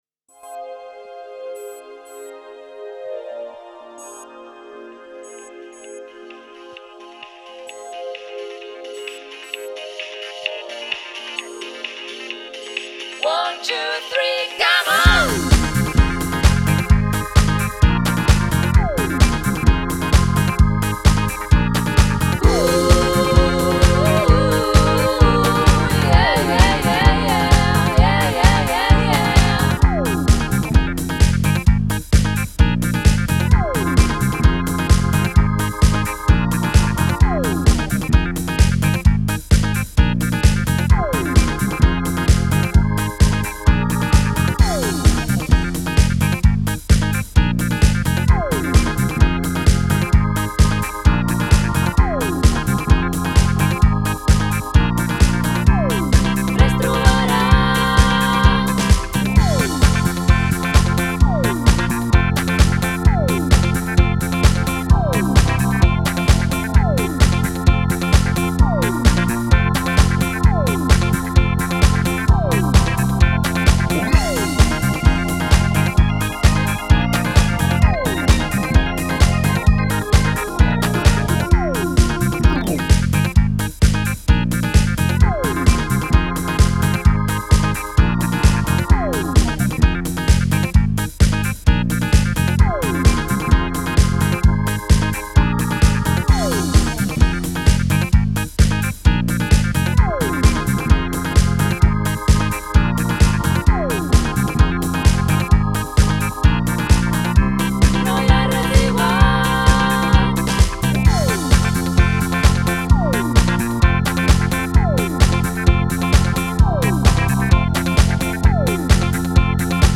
Versió karaoke: